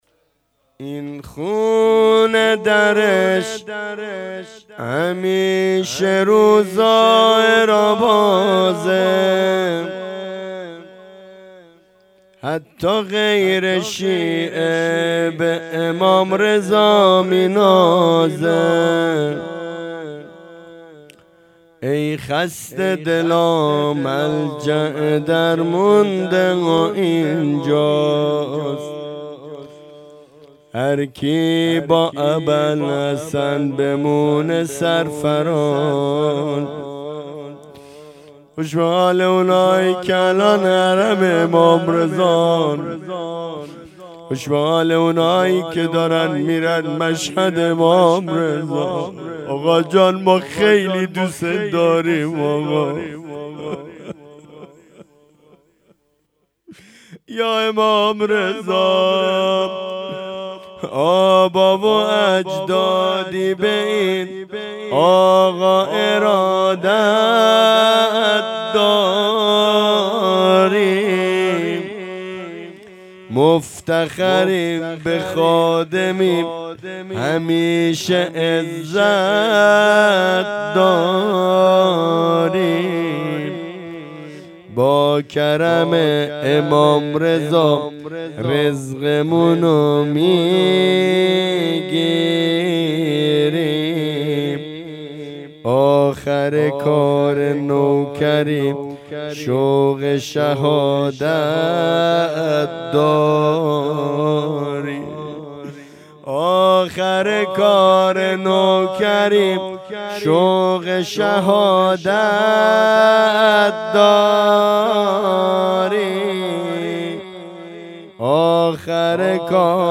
شهادت امام صادق علیه السلام 1404